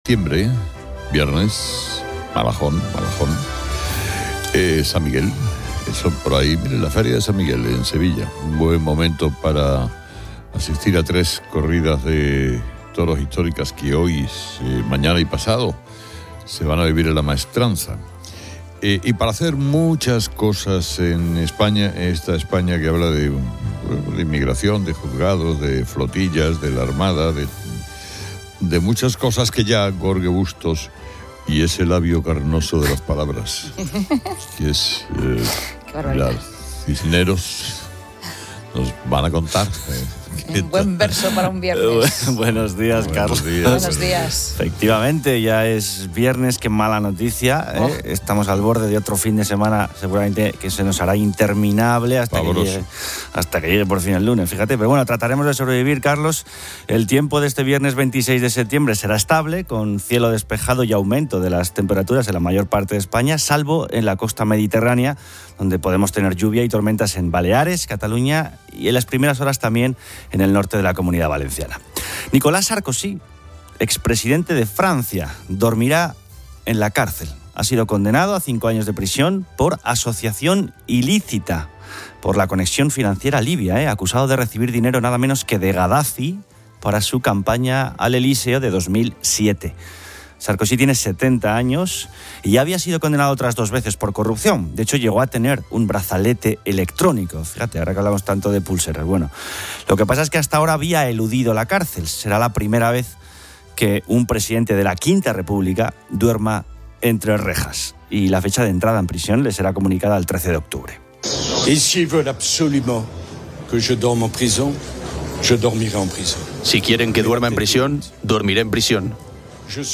Carlos Herrera inicia el programa analizando la condena de Nicolas Sarkozy en Francia a prisión por asociación ilícita, lo que genera un debate sobre la instrumentalización política de la justicia.